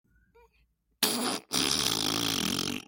Farting sound effect